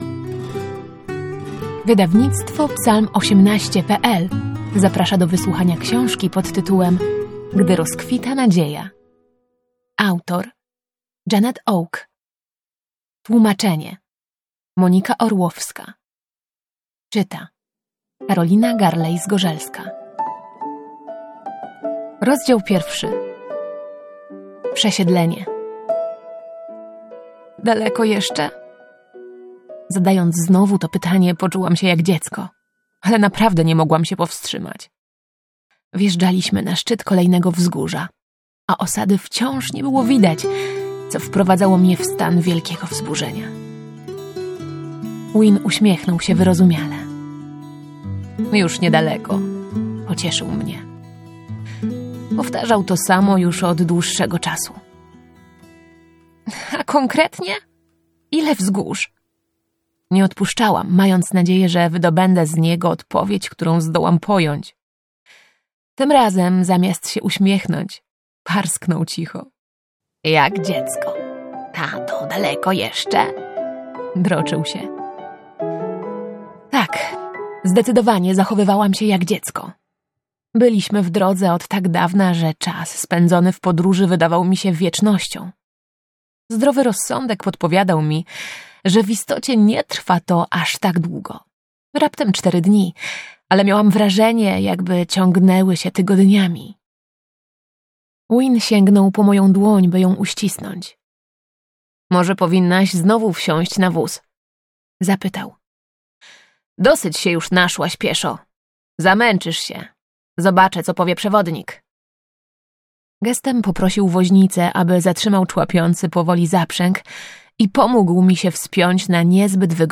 Gdy rozkwita nadzieja – Audiobook